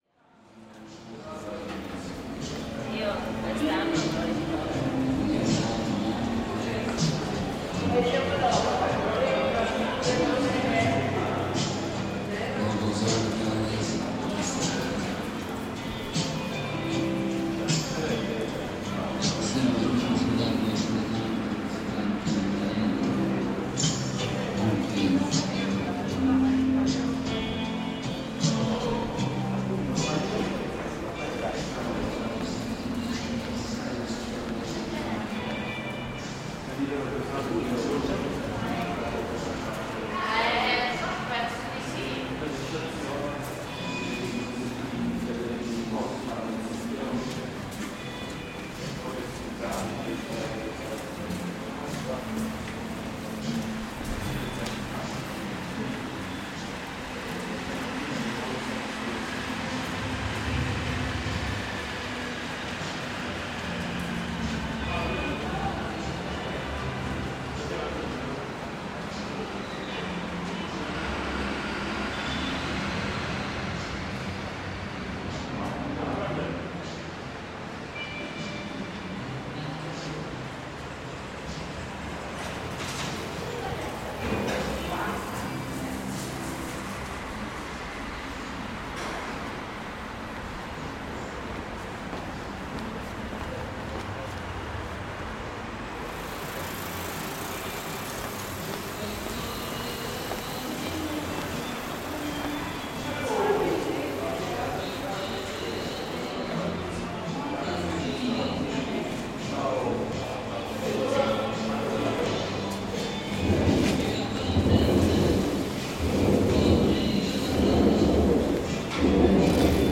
Sottopasso delle Cure in Florence, Italy.